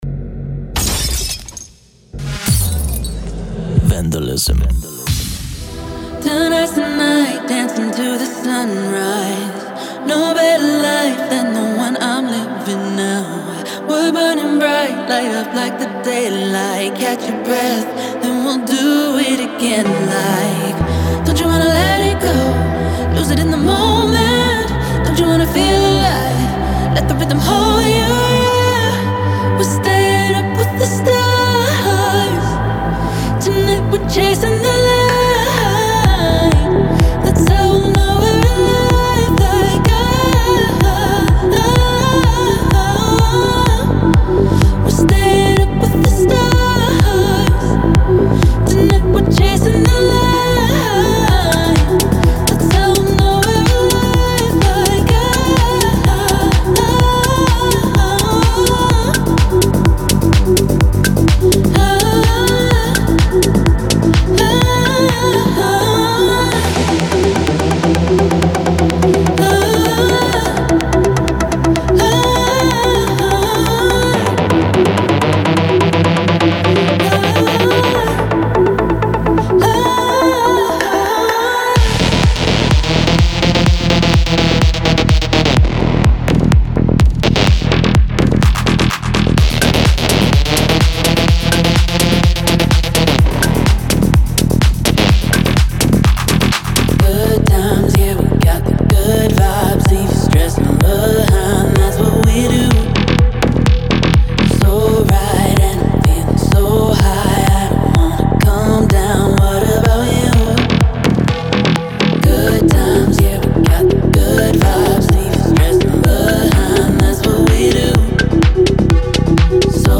Genre: Melodic Techno Progressive House Vocals